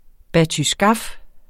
Udtale [ batyˈsgɑf ]